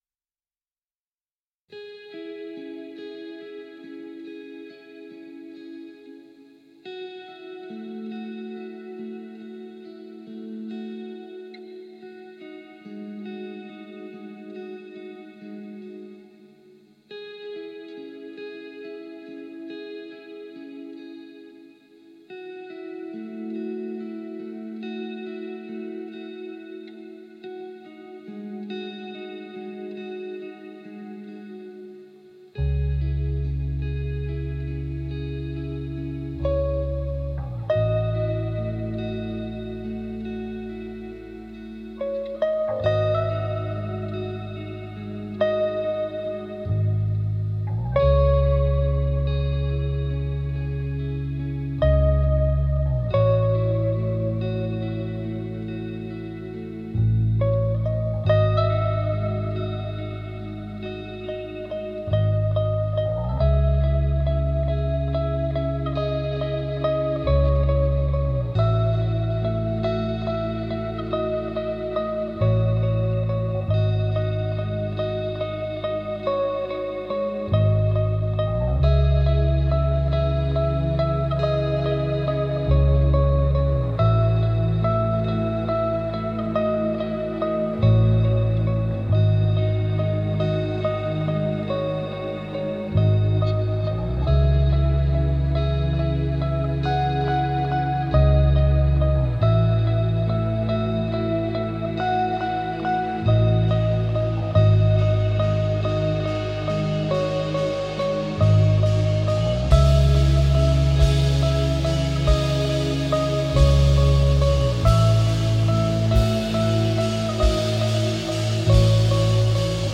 پست راک
Instrumental post rock